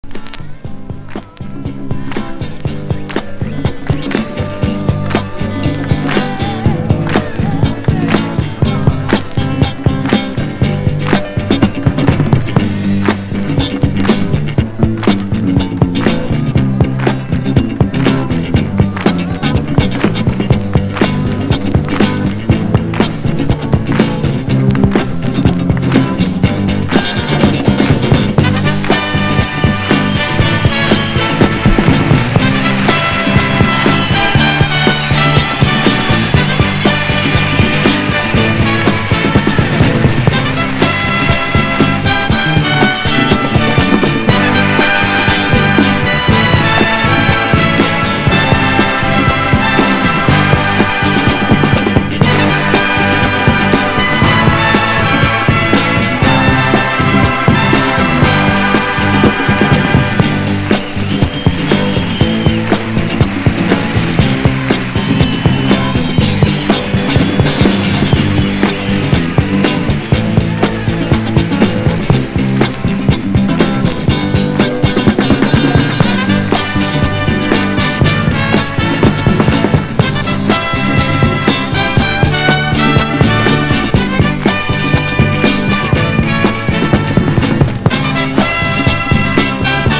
VOCAL & POPS
FRENCH / EURO POPS
どの曲もアップテンポでGOOD！2曲～3曲のメドレー形式で収録！